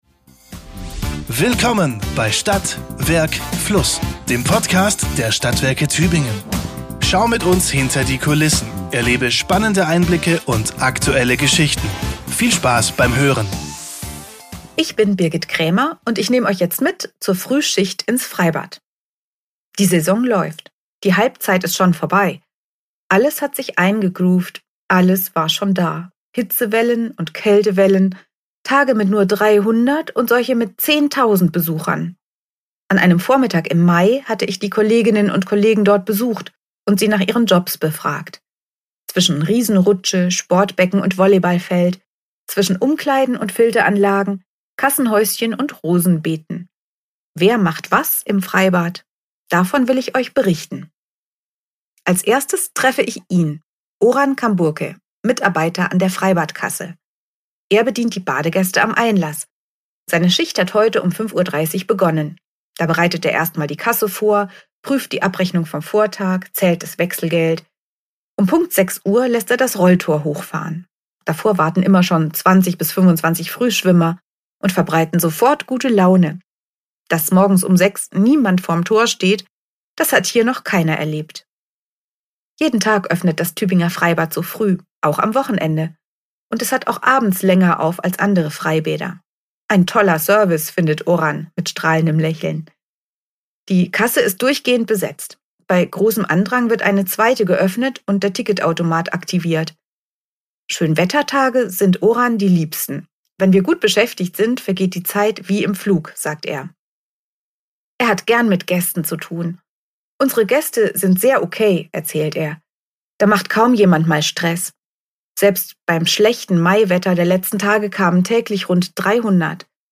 Zwischen Riesenrutsche, Sportbecken und Volleyballfeld, zwischen Umkleiden und Filteranlagen, Kassenhäuschen und Rosenbeeten. Wer macht was im Freibad?